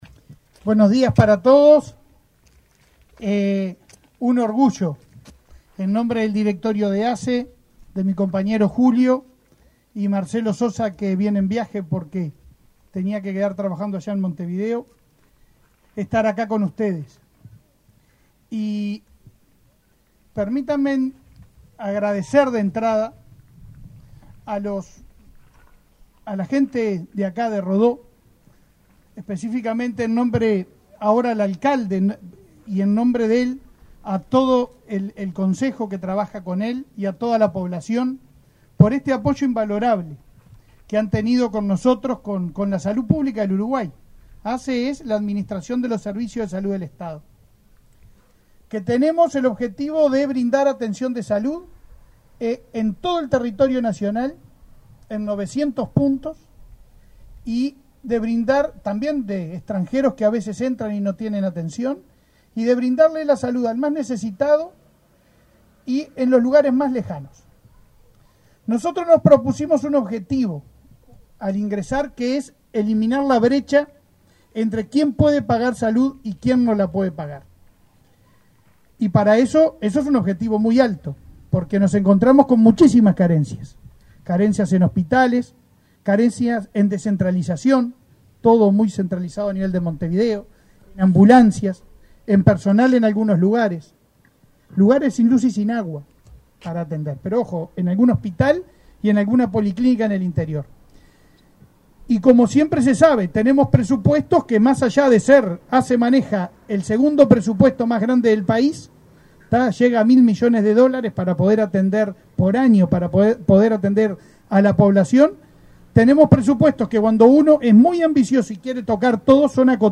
Palabras del presidente de ASSE, Leonardo Cipriani, en José Enrique Rodó
Palabras del presidente de ASSE, Leonardo Cipriani, en José Enrique Rodó 22/03/2022 Compartir Facebook X Copiar enlace WhatsApp LinkedIn El presidente de ASSE inauguró, este 22 de marzo, obras en la policlínica de José Enrique Rodo, en Soriano, que asiste mensualmente a unos 1.000 pacientes, y para la cual anunció la llegada de una ambulancia.